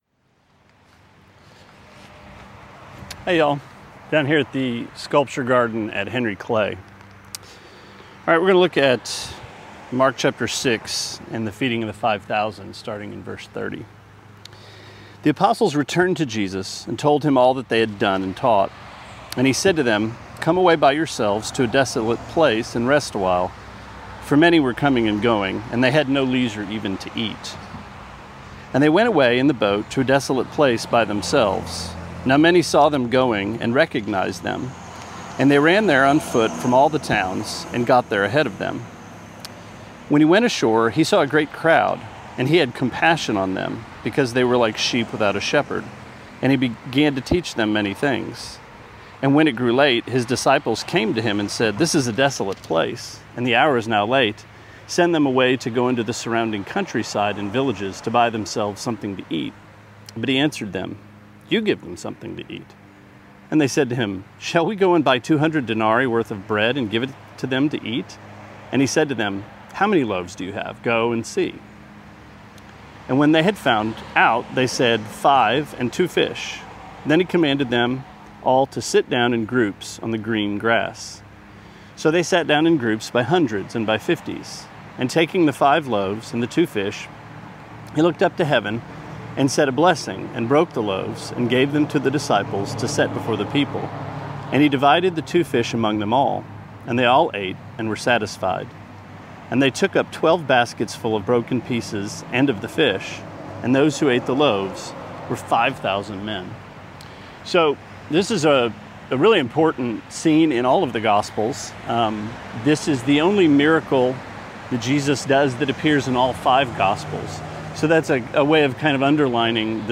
Sermonette A 7/6: Mark 6:30-44: Five Loaves, Two Fish